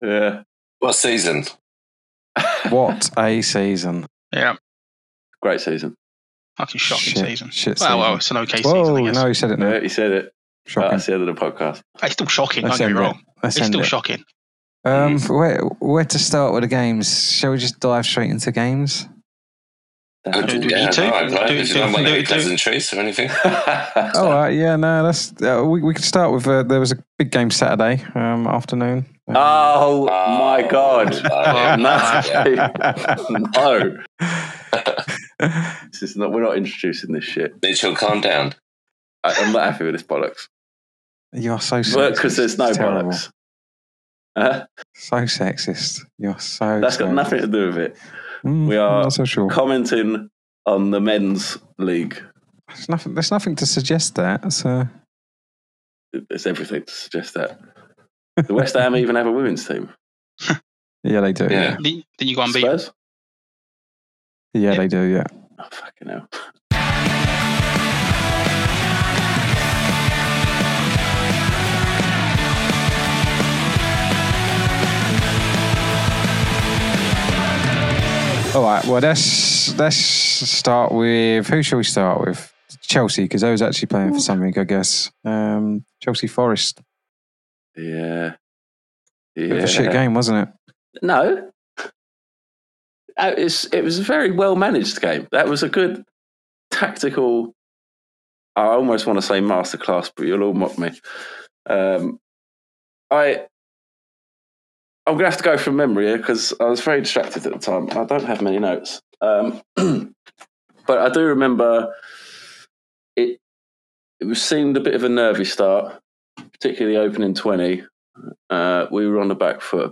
London Is... | A football podcast featuring four friends dissecting all things Arsenal, Chelsea, West Ham, and Spurs.